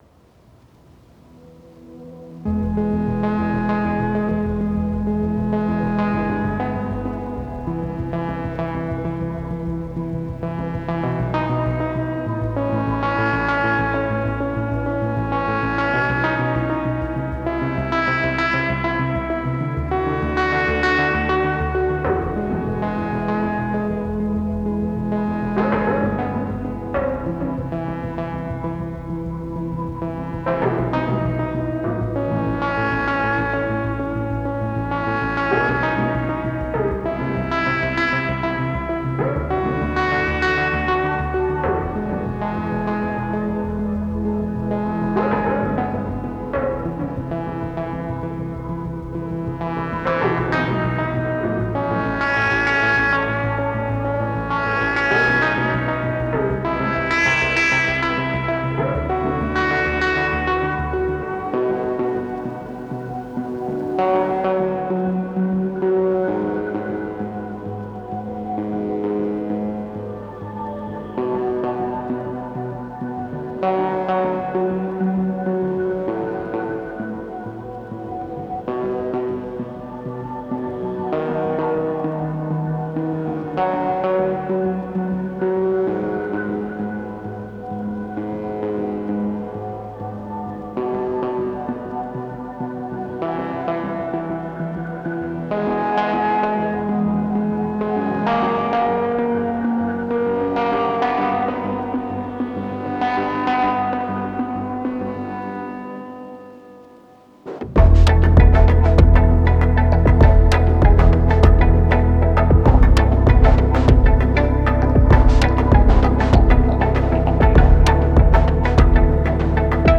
Uneasy, reality bending synth line with ambient soundscape.